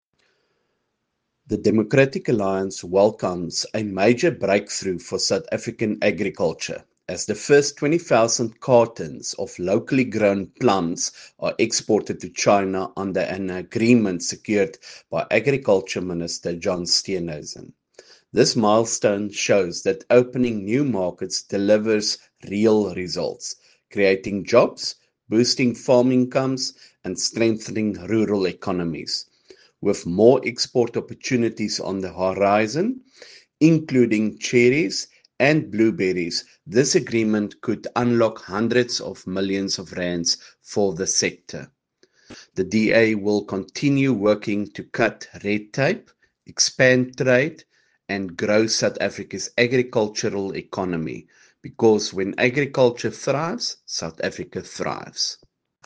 Issued by Beyers Smit MP – DA Spokesperson for Agriculture
Attention broadcasters: Attached here are audio clips by Beyers Smit MP in